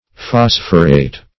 Search Result for " phosphorate" : The Collaborative International Dictionary of English v.0.48: Phosphorate \Phos"phor*ate\, v. t. [imp.